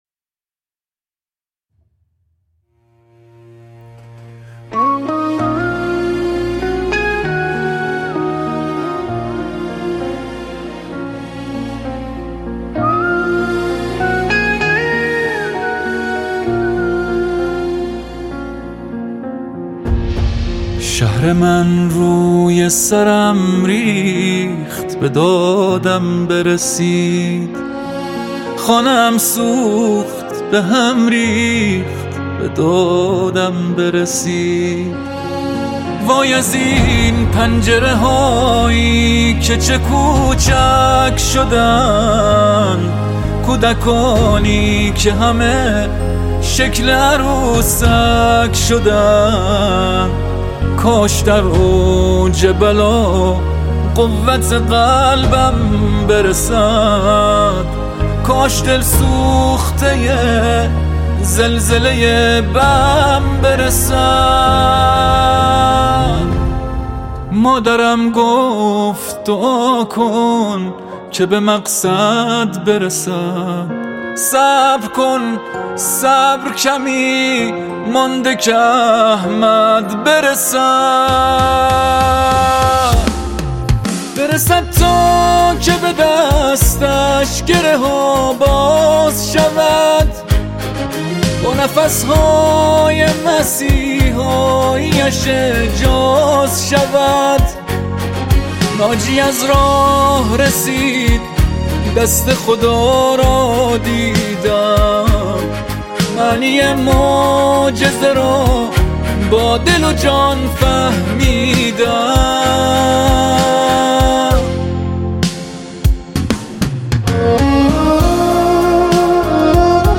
قطعه حماسی
فضای حماسی و در عین حال احساسی قطعه